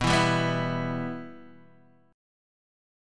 Bsus4#5 chord